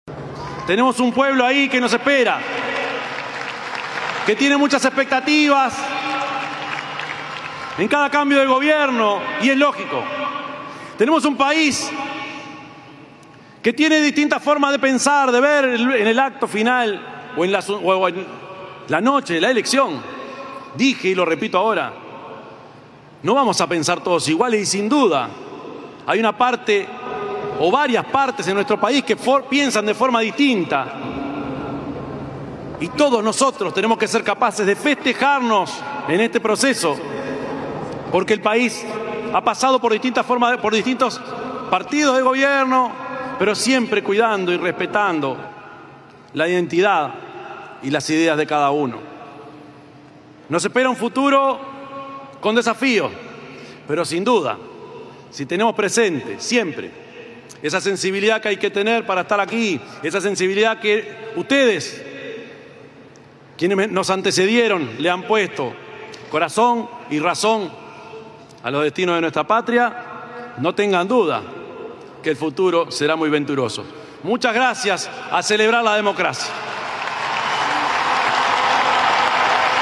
Luego del acto en el Parlamento, Orsi y Cosse se trasladaron a Plaza Independencia, donde fueron recibidos por el presidente saliente, Luis Lacalle Pou, y la exvicepresidenta Beatriz Argimón.
Desde Plaza Independencia, Orsi se dirigió a la ciudadanía y destacó su orgullo por ser parte de la sociedad uruguaya y la importancia de considerar a todo el territorio nacional en la gestión del país.